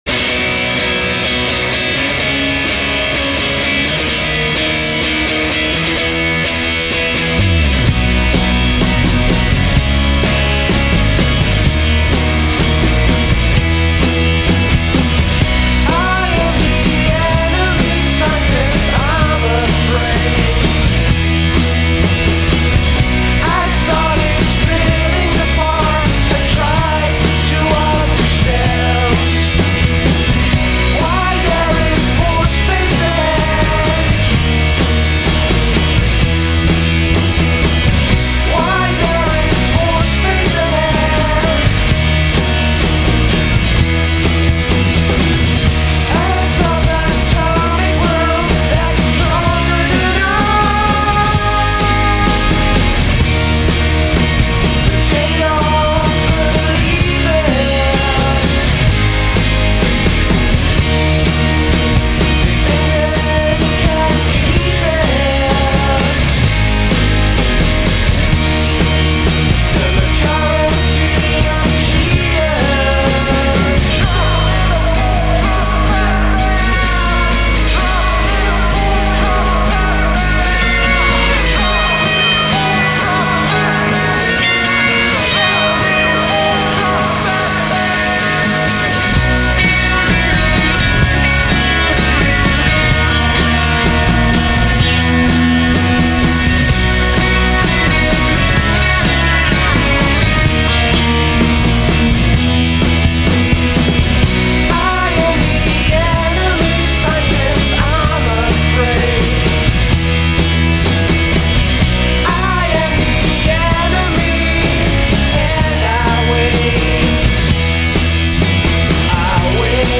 klinkt het lekker fris